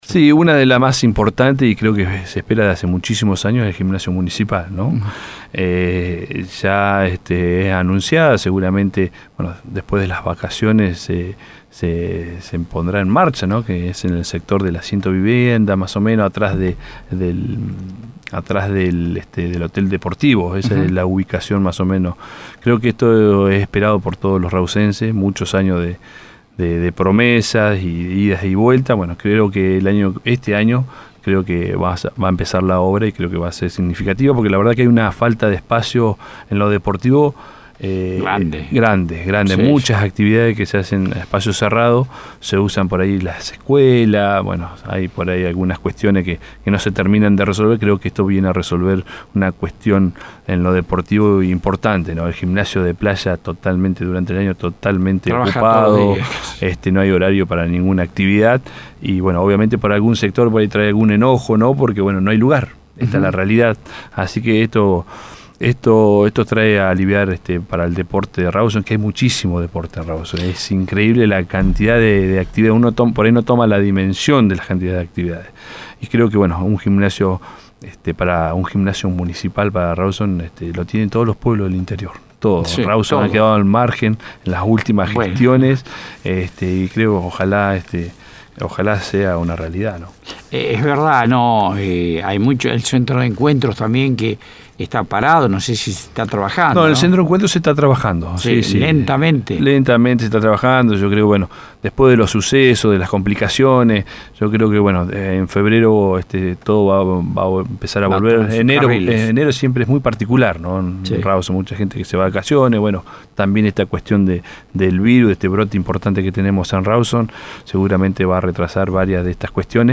En comunicación con Bahía Engaño, el Secretario de Gobierno, Miguel Larrauri, habló acerca de diversos temas en el programa de Piedra Libre.